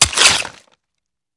swipe3.wav